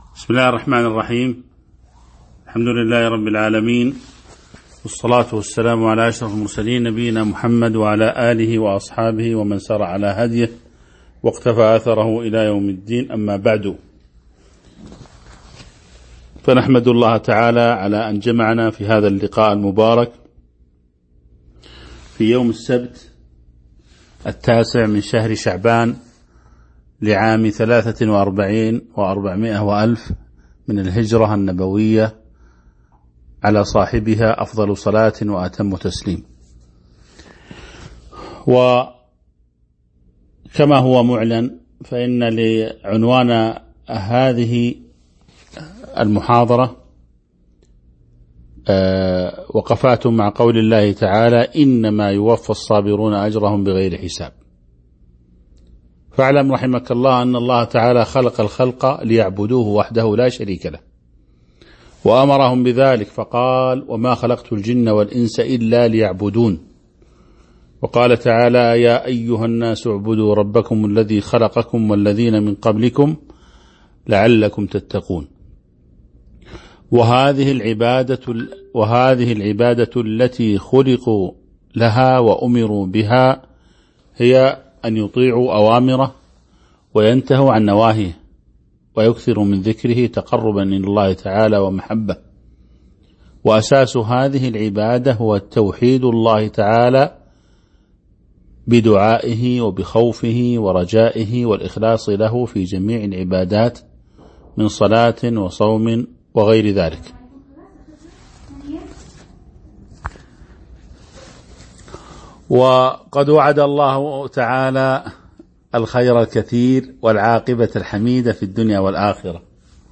تاريخ النشر ٩ شعبان ١٤٤٣ هـ المكان: المسجد النبوي الشيخ